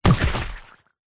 melee.wav